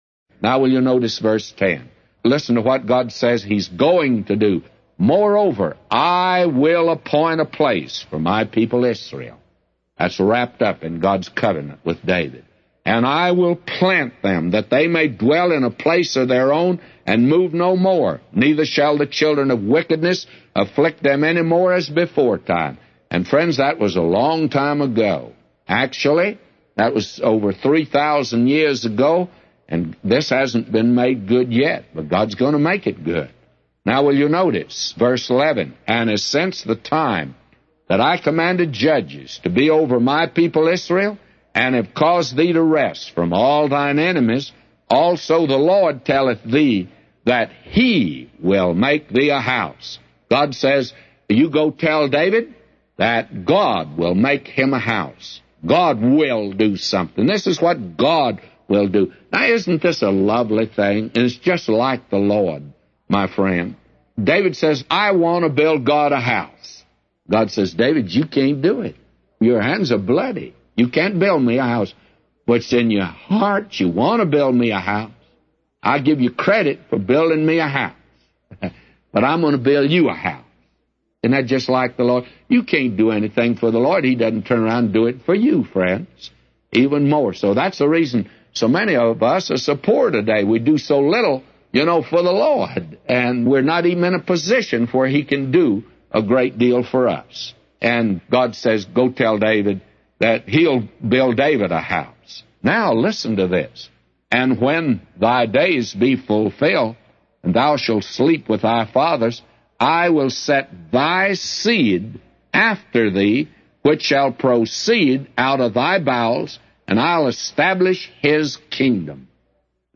A Commentary By J Vernon MCgee For 2 Samuel 7:10-999